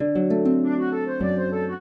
flute-harp